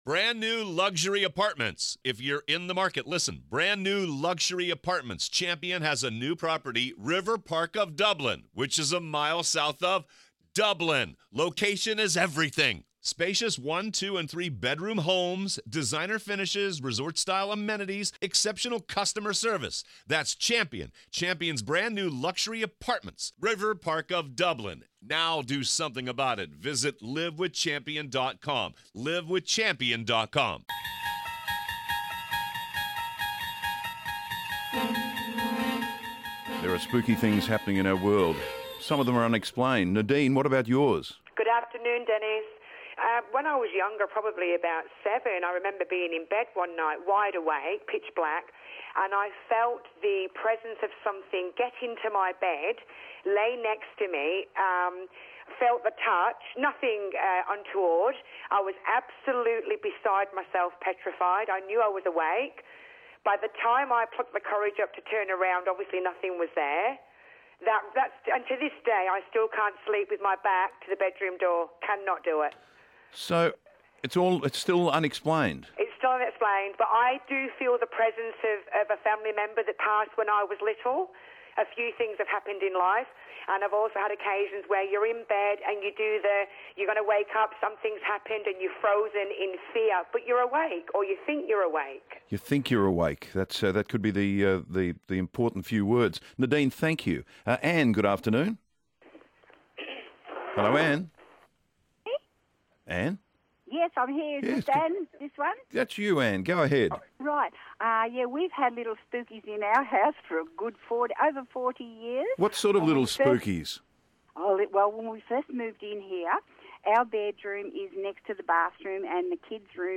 More listeners tell us about their spooky encounters...